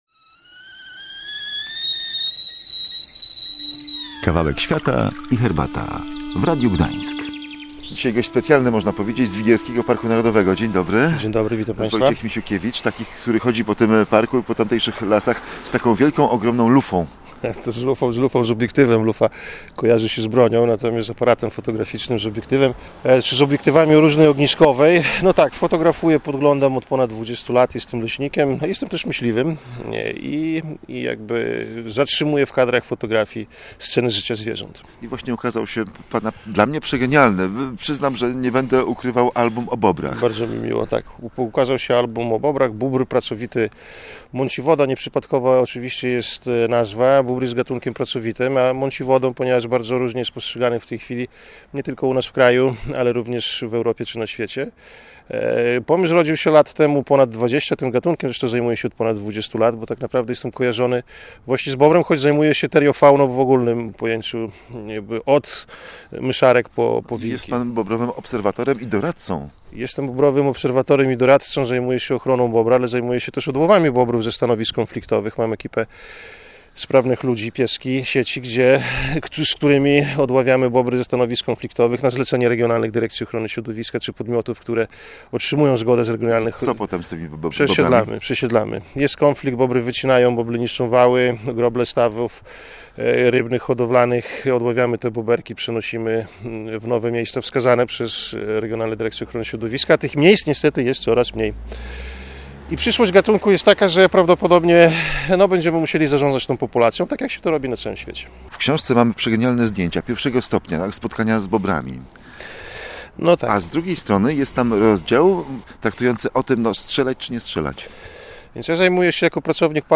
Bóbr – pracowity mąciowoda, genialne zdjęcia i gawędziarska opowieść o tajemniczych zwierzętach